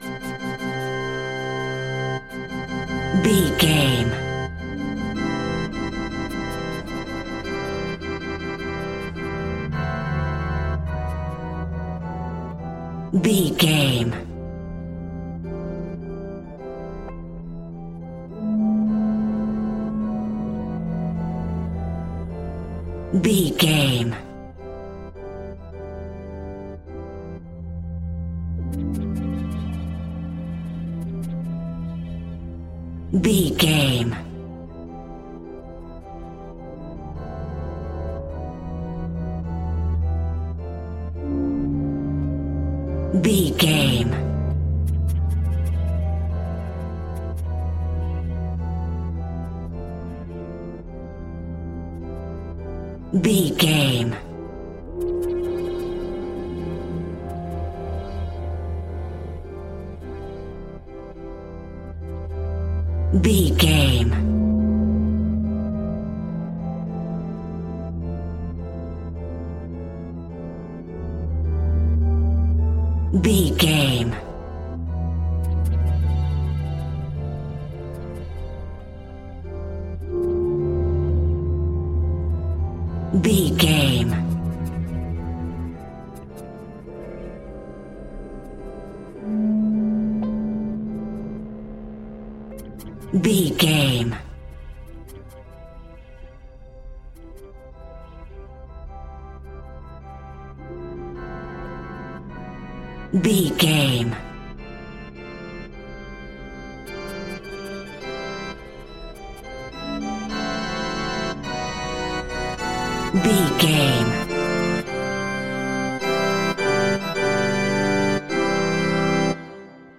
Ionian/Major
scary
ominous
dark
haunting
eerie
organ
synthesiser
ambience
pads